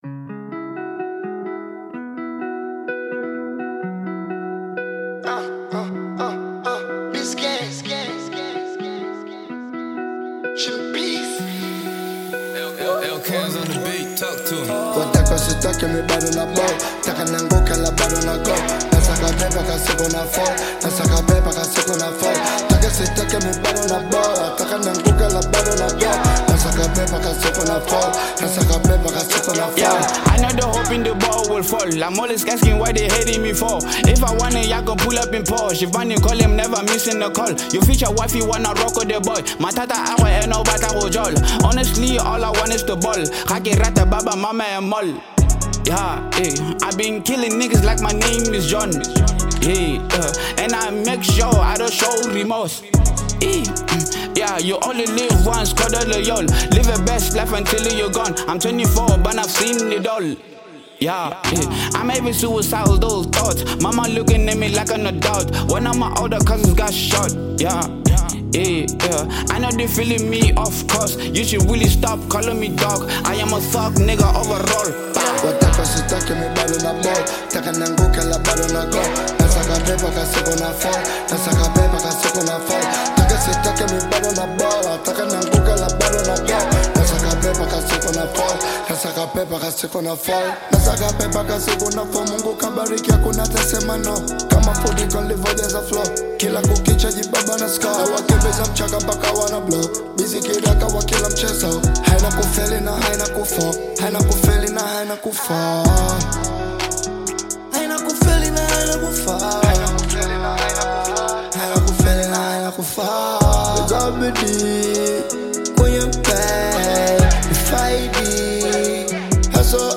Tanzanian bongo flava trap singer and songwriter
African Music